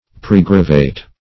Search Result for " pregravate" : The Collaborative International Dictionary of English v.0.48: Pregravate \Pre"gra*vate\, v. t. [L. praegravatus, p. p. of praegravare to be heavy upon, fr. praegravis very heavy.] To bear down; to depress.